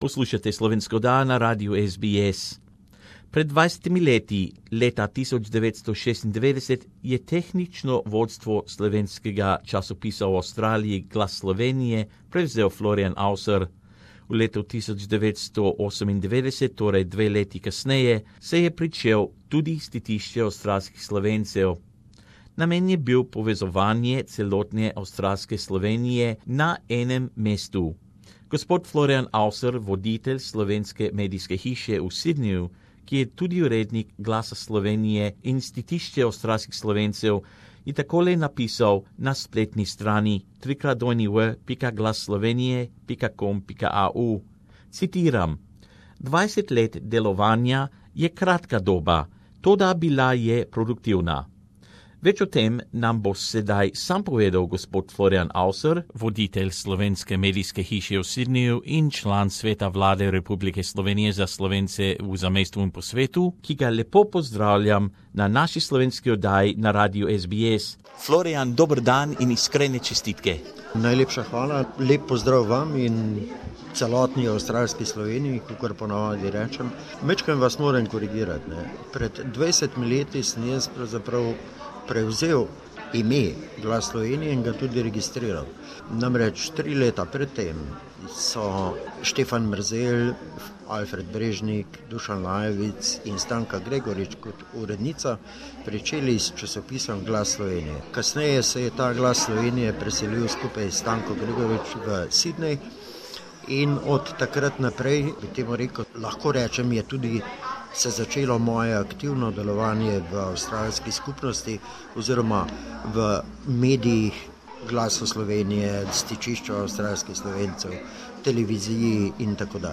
In this interview, he speaks to us about the importance of 'Glas Slovenije' as a vital source of information for anyone who requires information about the Slovenian community in Australia that is available in one convenient online address!